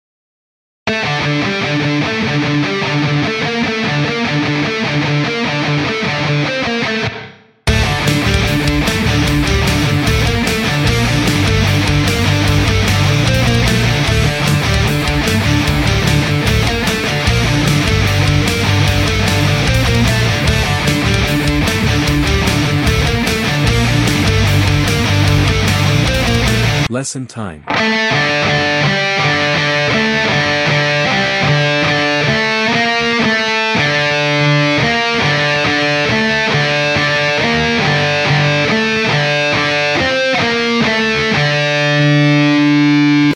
Here's a bit of straightforward enjoyment on the D string. It's in standard tuning.